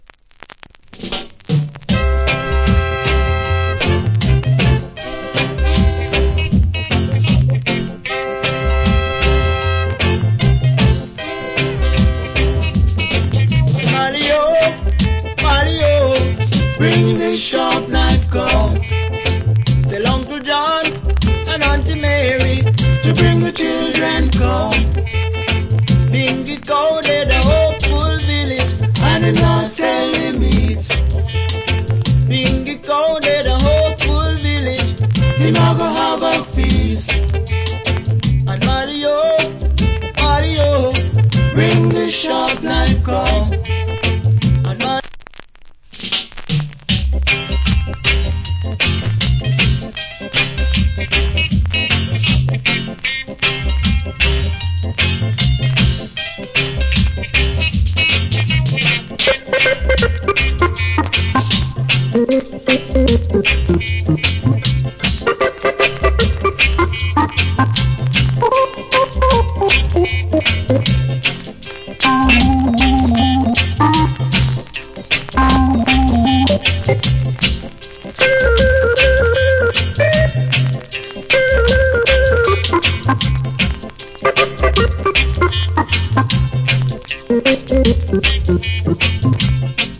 Cool Early Reggae Vocal.